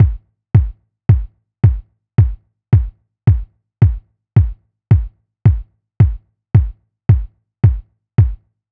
Unison Funk - 10 - 110bpm - Kick.wav